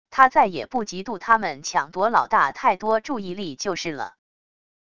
他再也不嫉妒他们抢夺老大太多注意力就是了wav音频生成系统WAV Audio Player